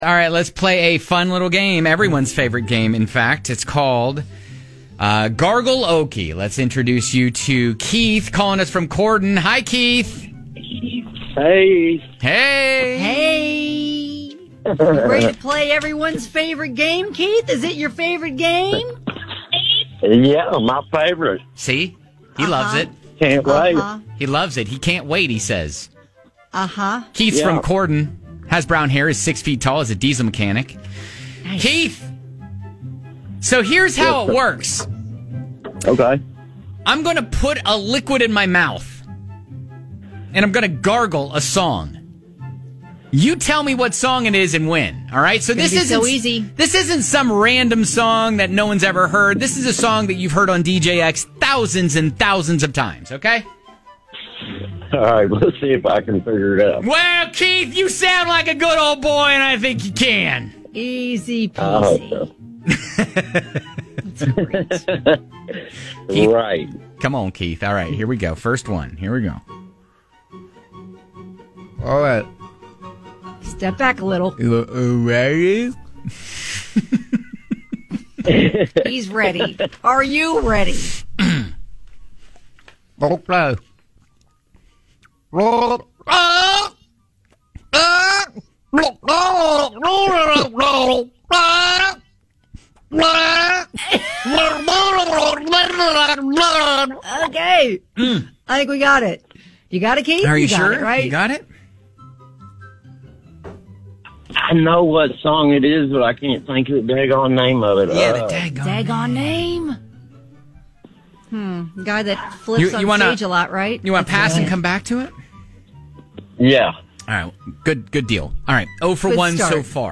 We gargle songs - you guess them.